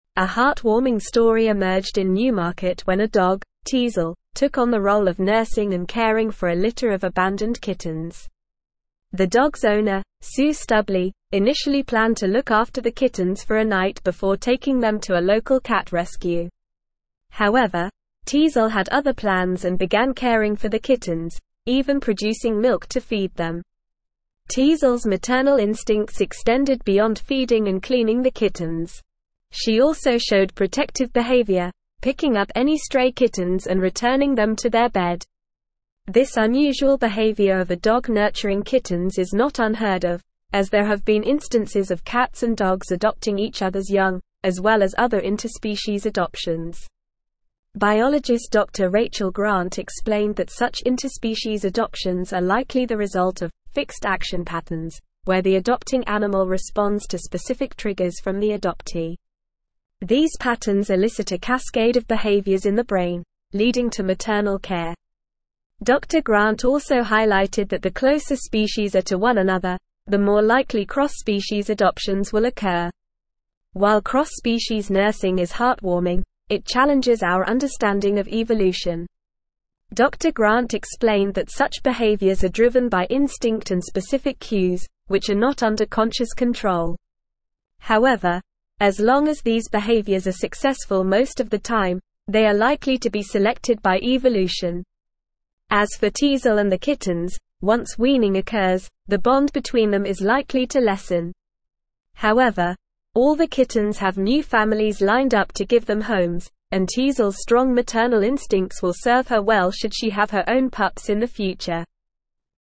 Normal
English-Newsroom-Advanced-NORMAL-Reading-Dog-Becomes-Surrogate-Mother-to-Abandoned-Kittens.mp3